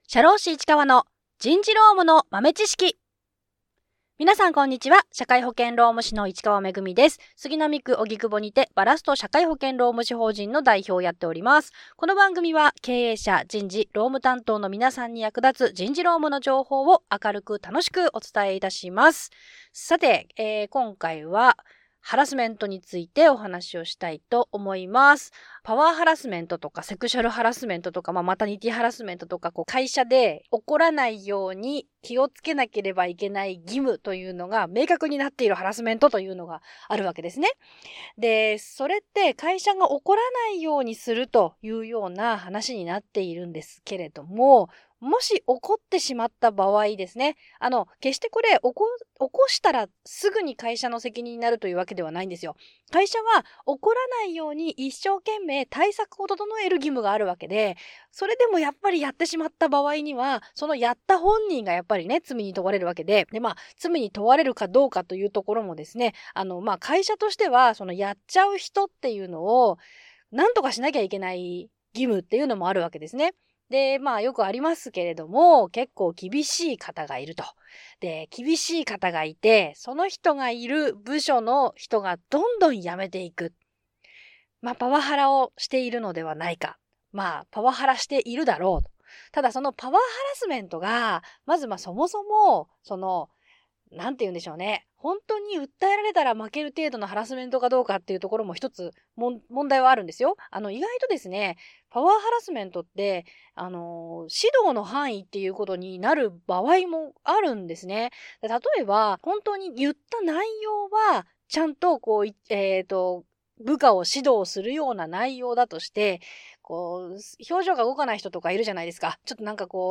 ※編集の都合上音楽が入ってません 会社に義務付けられているハラスメント対策ですが、ハラスメントした社員を解雇にしたら、解雇無効になった、という判例があるのをご存じですか？